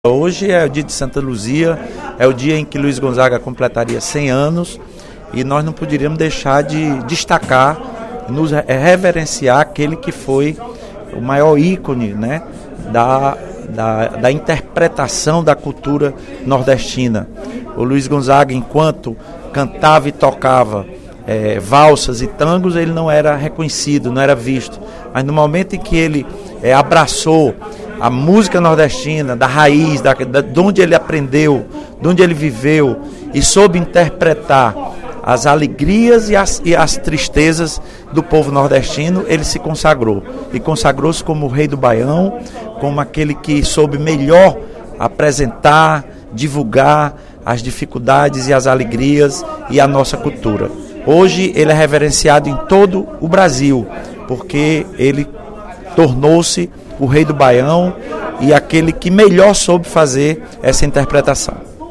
O deputado Lula Morais (PCdoB) homenageou, durante o primeiro expediente da Assembleia Legislativa, desta quinta-feira (13/12), os 100 anos de nascimento do pernambucano Luiz Gonzaga, o Rei do Baião.